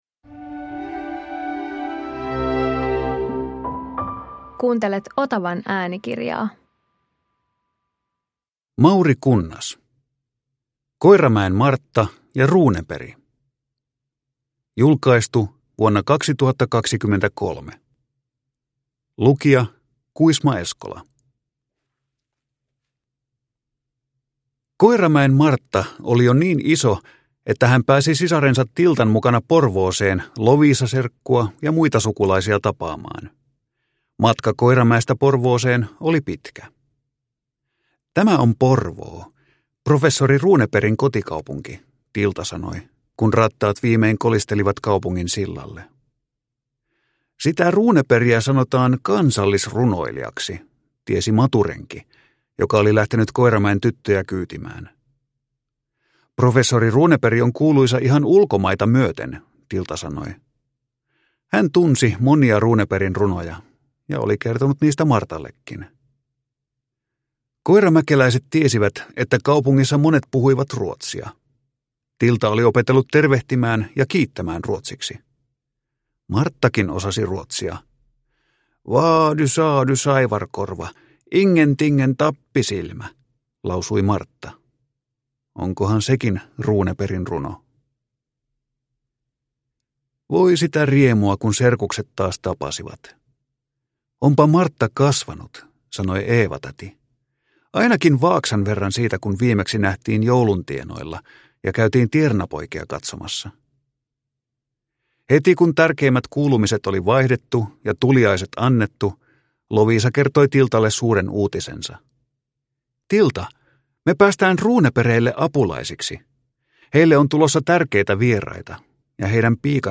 Koiramäen Martta ja Ruuneperi – Ljudbok